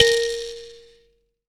Index of /90_sSampleCDs/AKAI S6000 CD-ROM - Volume 5/Africa/KALIMBA